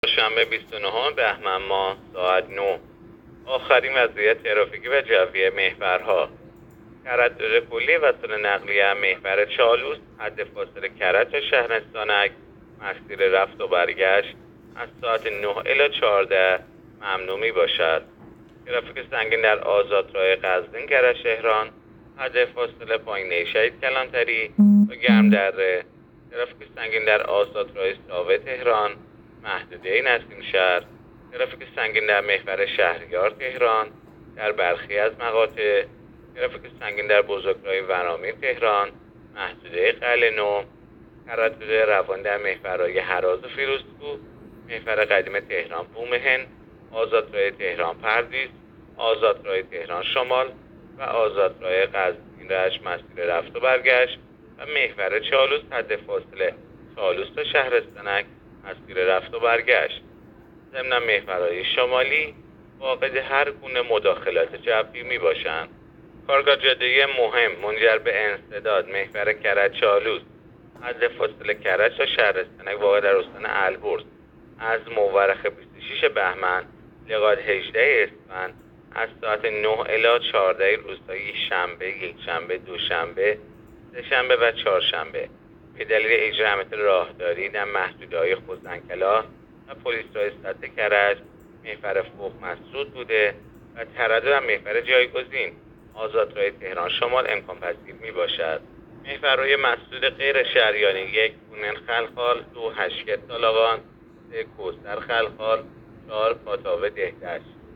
گزارش رادیو اینترنتی از آخرین وضعیت ترافیکی جاده‌ها ساعت ۹ بیست و نهم بهمن؛